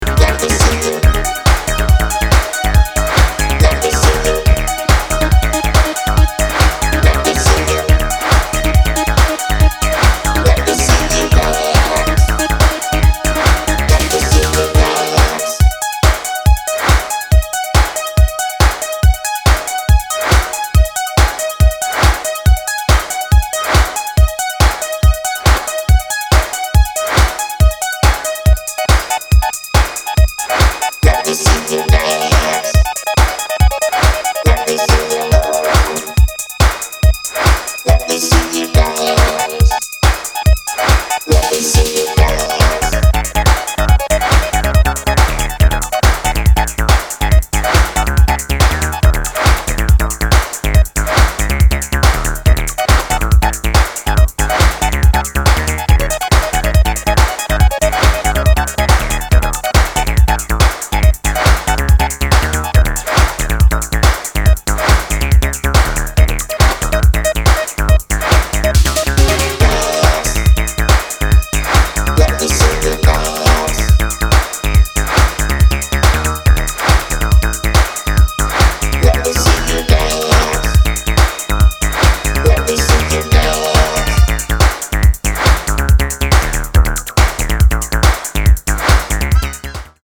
Commanding you to the dance floor.